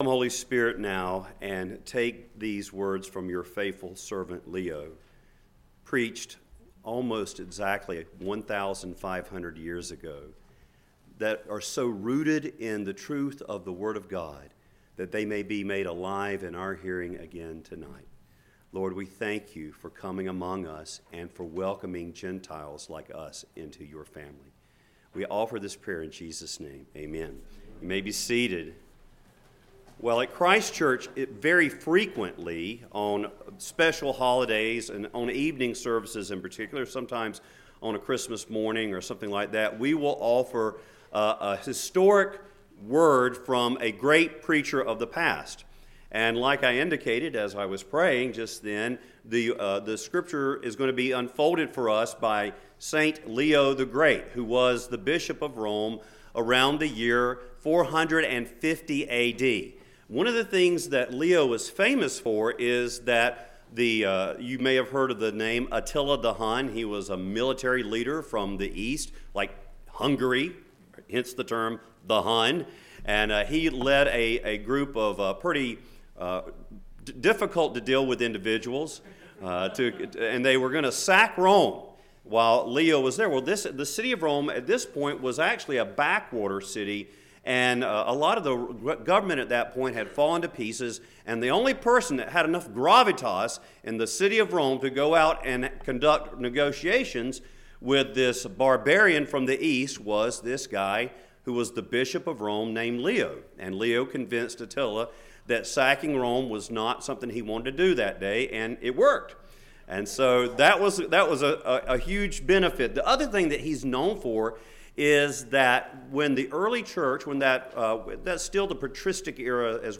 Epiphany Sermon of Saint Leo the Great